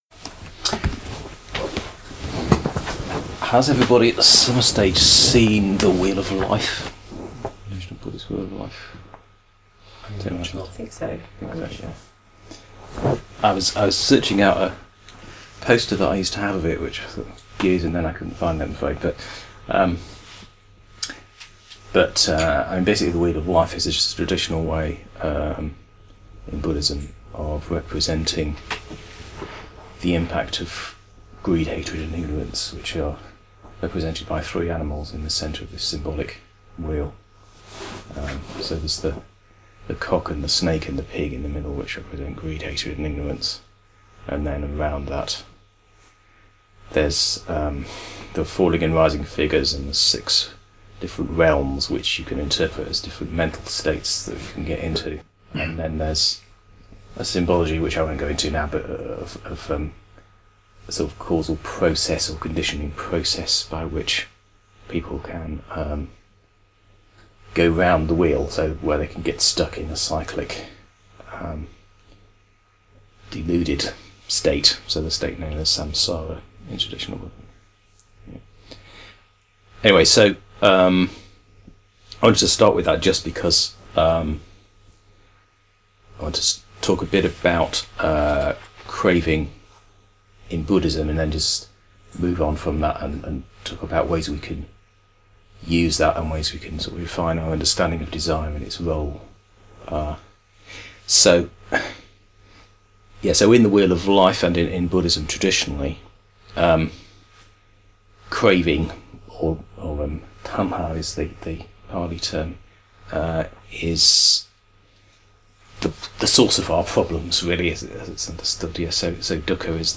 This page contains recordings (enhanced with pictures) from day 2 of the Middle Way Study Retreat held in August 2013. A talk about desire and integration was followed by discussion (with some bits of discussion spliced into the relevant sections of the talk).